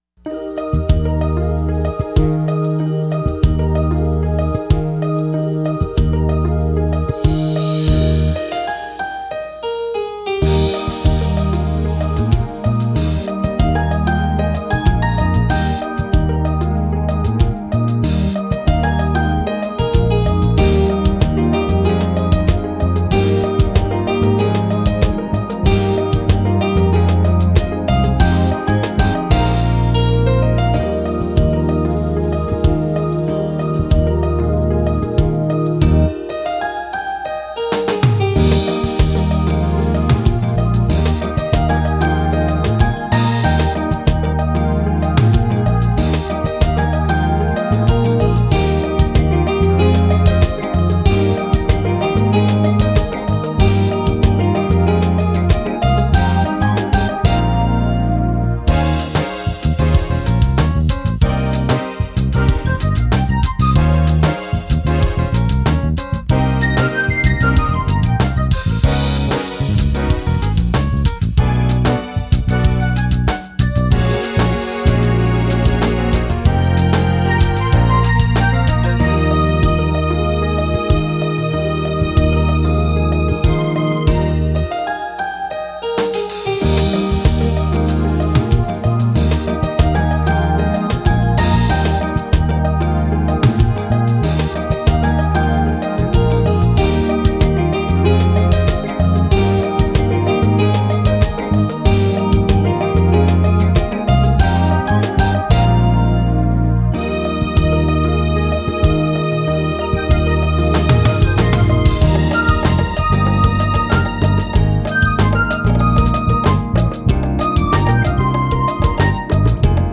lead synthesizer and synthesizer solos.
synthesizer accompaniment
electric bass and drum-programming.